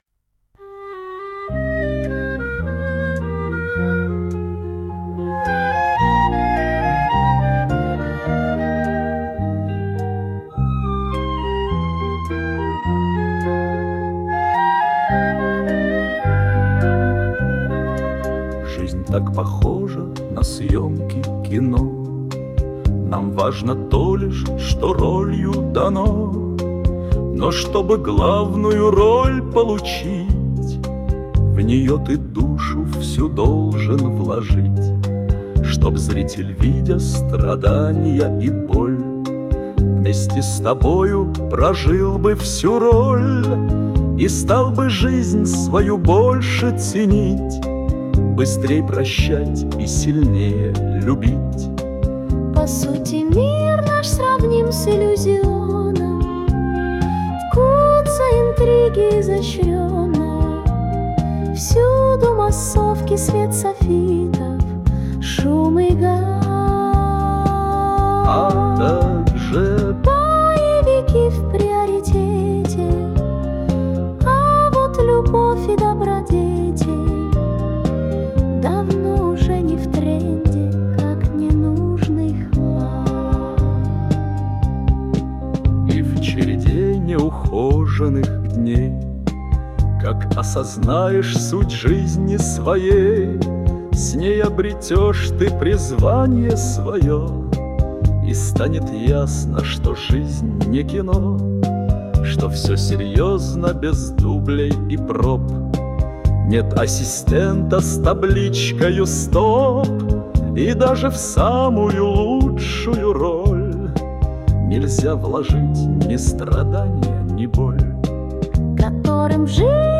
кавер-версия